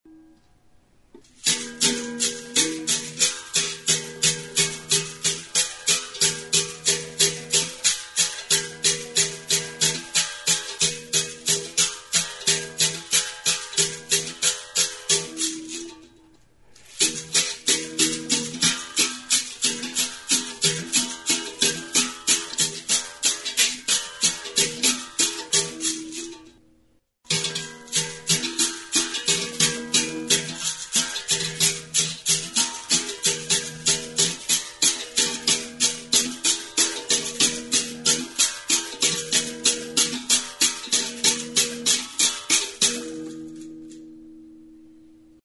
Enregistr� avec cet instrument de musique.
BERIMBAU; BERIMBAU DE BARRIGA; URUCUNGU
Kalabaza dekoratua da; makila, metalezko arandela eta caxixia (maraka gisako saskitxo itxia) ditu. Arkuan metalezko soka du.